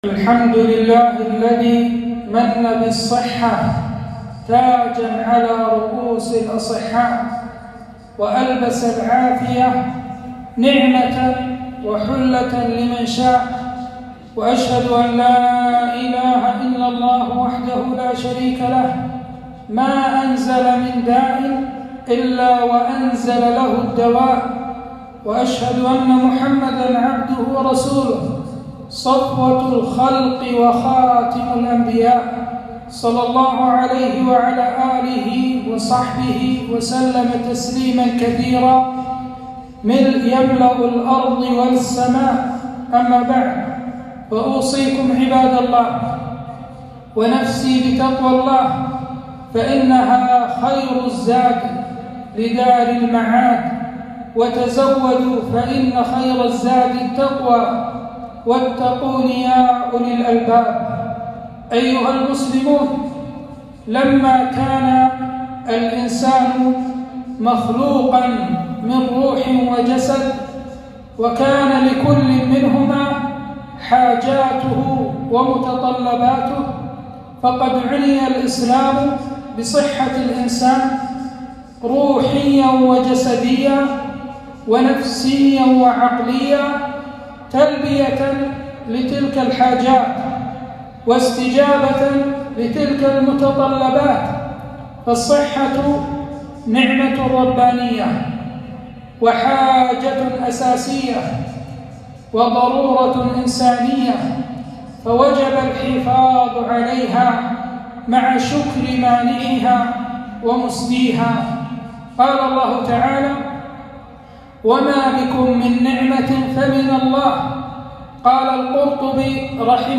خطبة - عناية الإسلام بصحة الإنسان